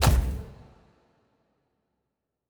Special Click 09.wav